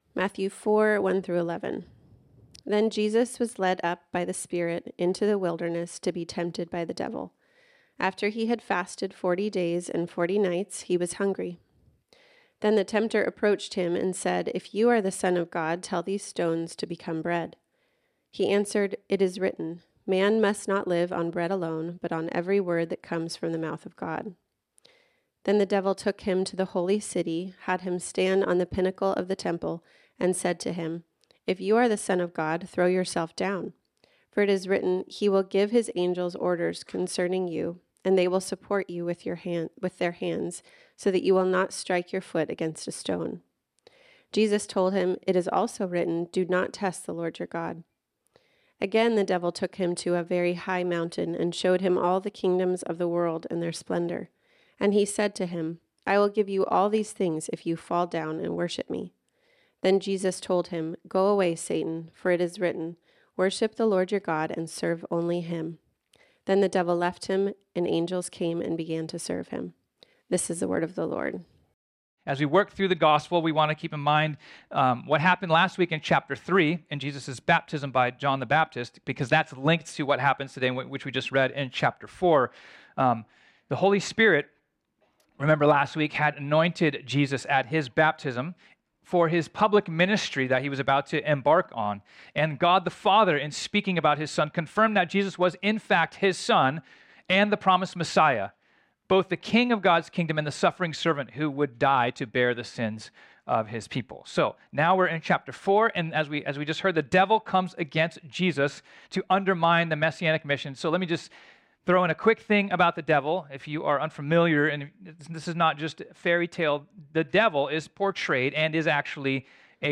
This sermon was originally preached on Sunday, January 14, 2024.